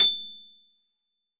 piano-ff-65.wav